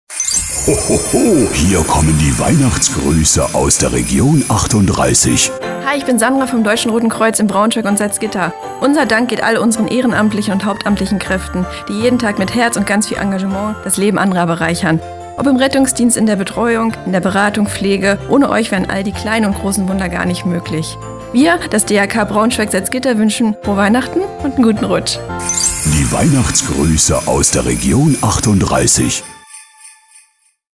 Heute nehmen wir Euch mit ins Studio von Radio38, wo wir einen besonderen Weihnachtsgruß einsprechen durften! Unser Gruß läuft den ganzen Dezember über im Radio – habt Ihr uns schon gehört?
XMAS-GRUESSE_DRK.mp3